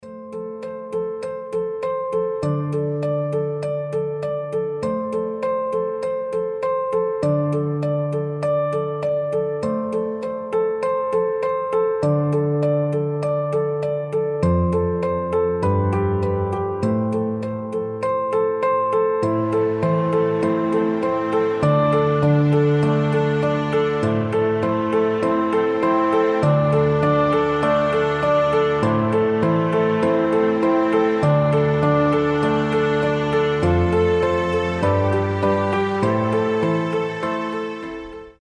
• Качество: 320, Stereo
красивые
без слов
пианино
тиканье часов
рождественские